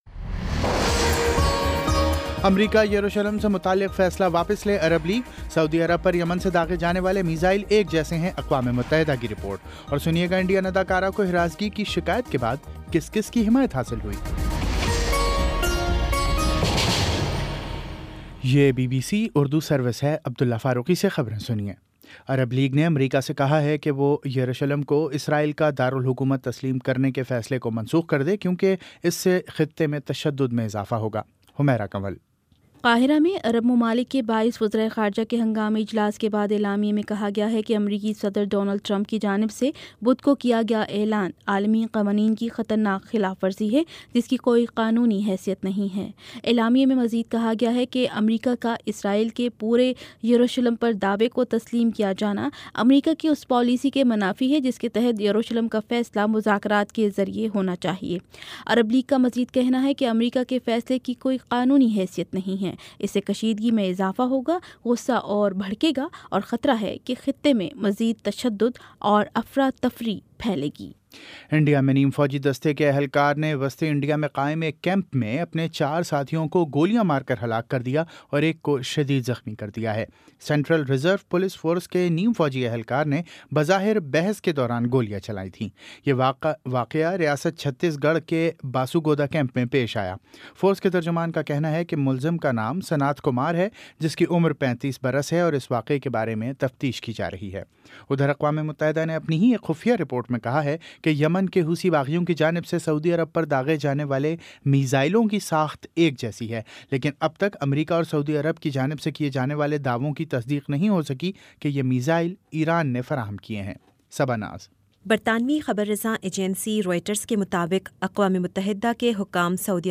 دسمبر 10 : شام سات بجے کا نیوز بُلیٹن